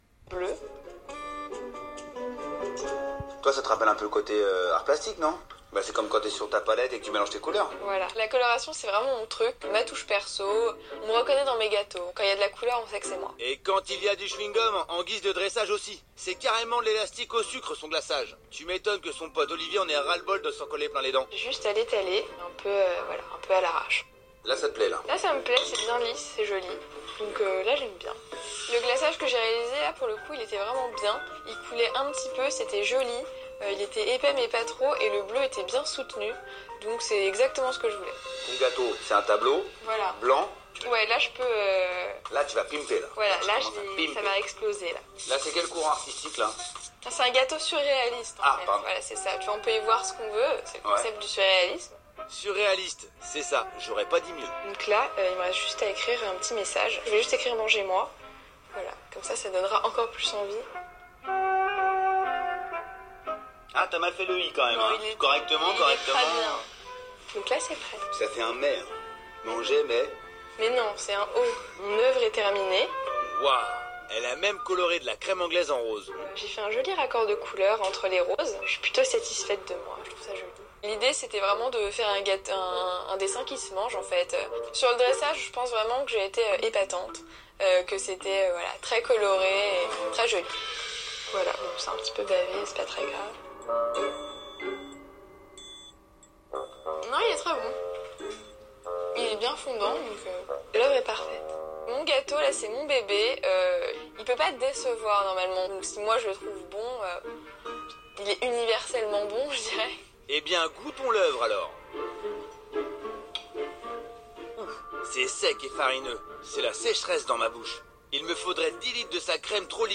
Mais ça pourrait vous plaire aussi parce que c’est une vraie leçon de français oral!
Ou juste le son si ce n’est pas accessible de votre pays ou quand ça aura disparu du site de l’émission.